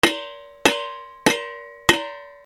なべを叩く